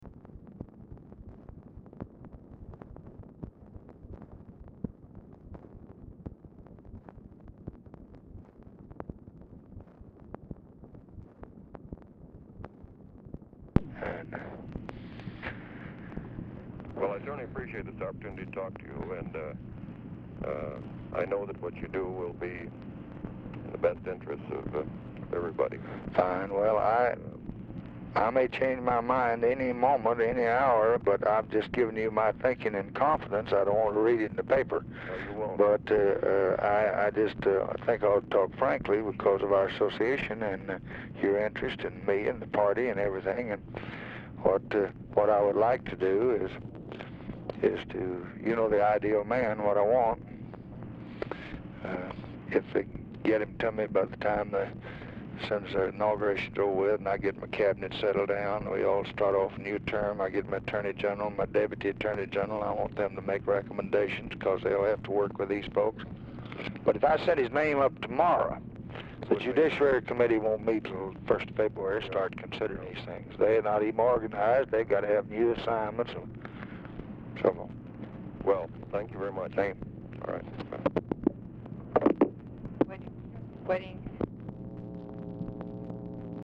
Telephone conversation # 6732, sound recording, LBJ and PAT LUCEY, 1/14/1965, 12:04PM
Format Dictation belt
Location Of Speaker 1 Oval Office or unknown location